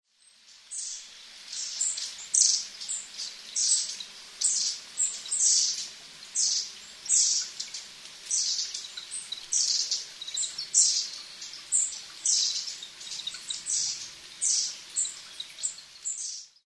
enaga_c1.mp3